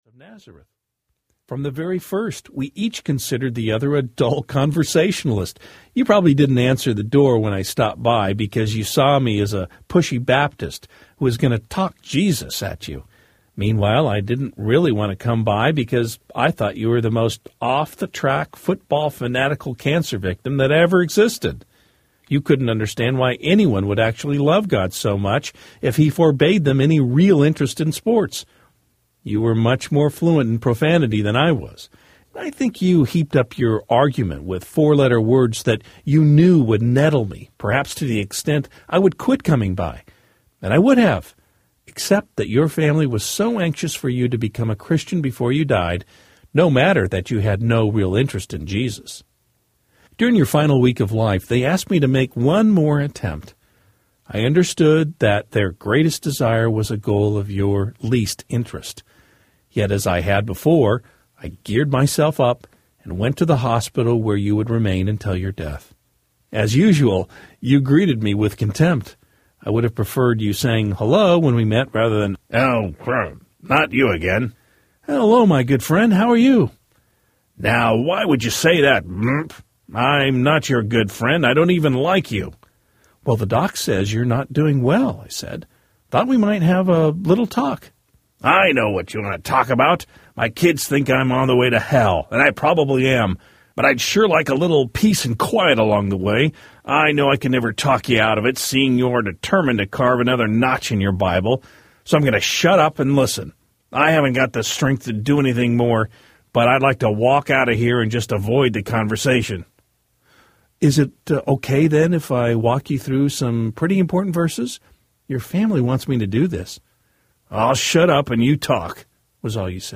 Letters to Heaven Audiobook
Narrator
4.2 Hrs. – Unabridged